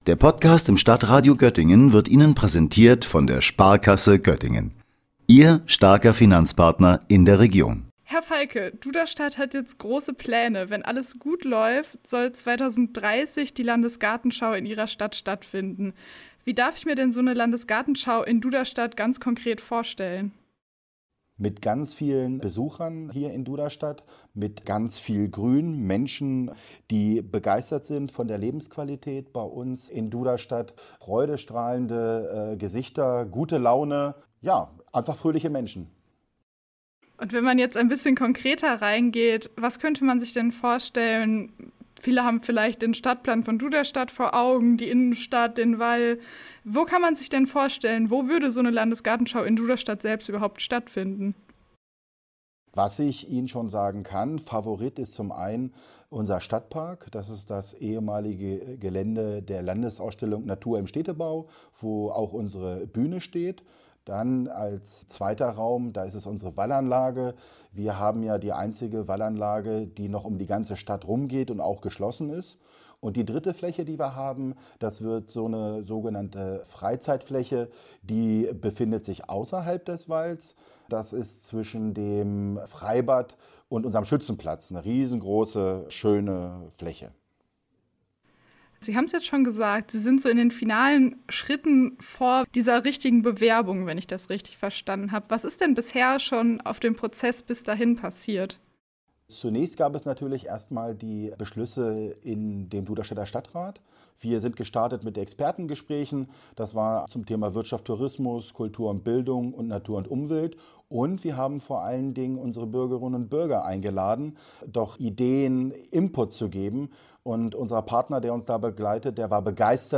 Gerade läuft eine Machbarkeitsstudie, später im Jahr soll es dann an die konkrete Bewerbung gehen. Duderstadts Bürgermeister Thorsten Feike war bei uns zu Gast und verrät, wie es um die Bewerbung steht und wie es in diesem Jahr weitergeht.